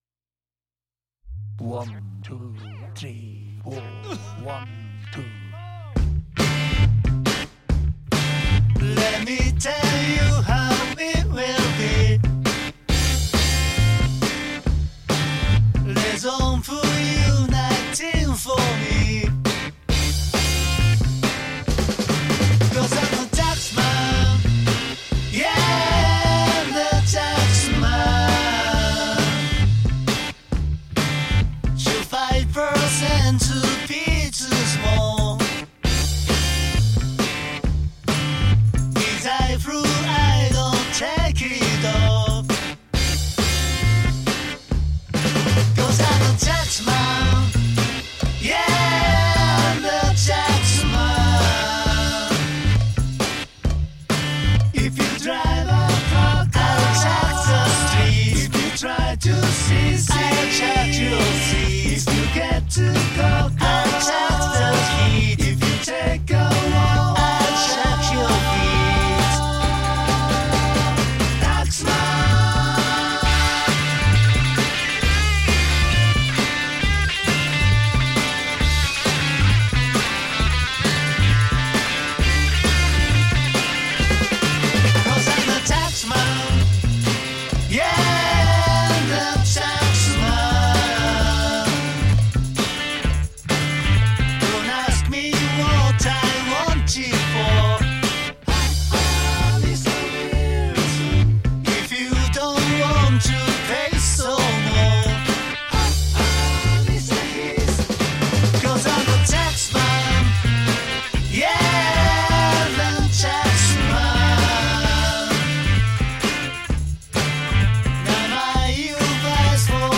1st G ：Epiphone Casino
2nd G：Fender Lapan Telecaster
BassG：Rickenbacker 4003
Percussion ：Tambourine&Cowbell
MTR：Zoom MRS1266
Amp Simulater：Line6 POD2
い〜音出てます！